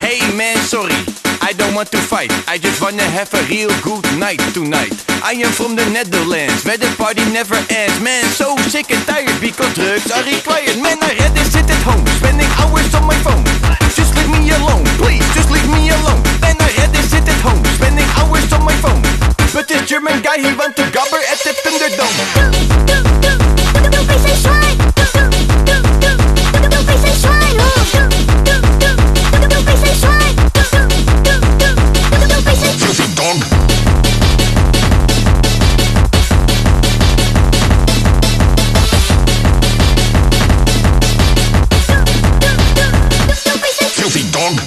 Blah Blah Blah Blah Woof Sound Effects Free Download